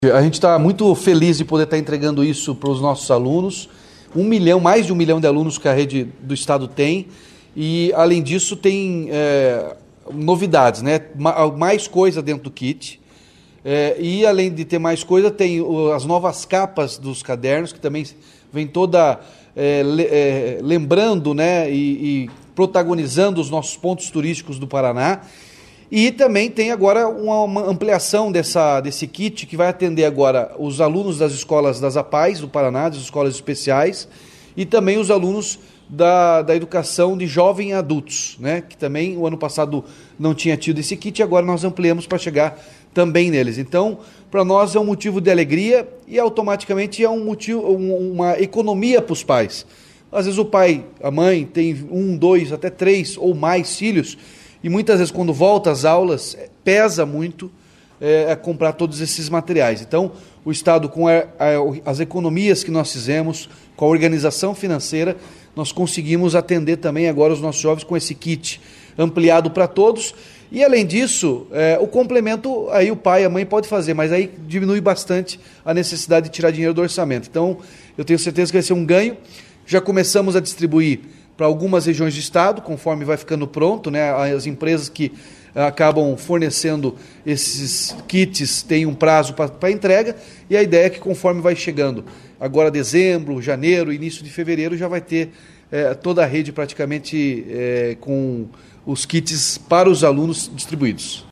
Sonora do governador Ratinho Junior sobre a entrega de kits para alunos da rede estadual em 2026